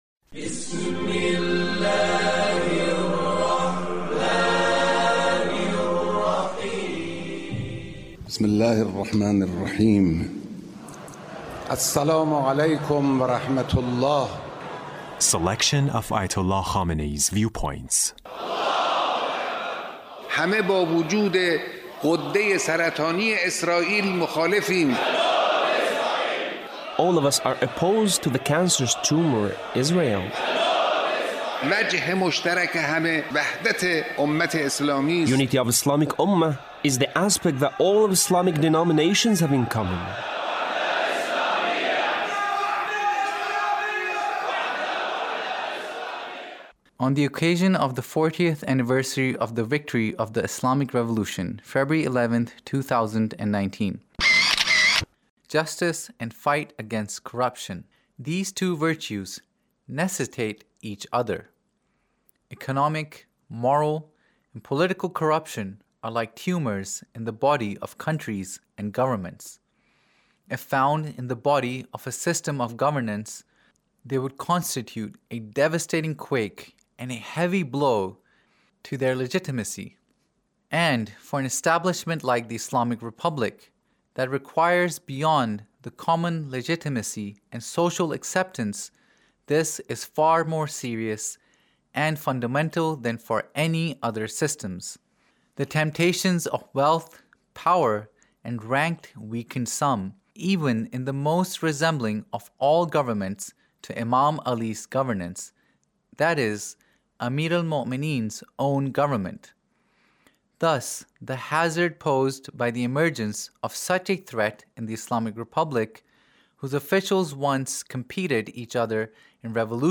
Leader's Speech (1896)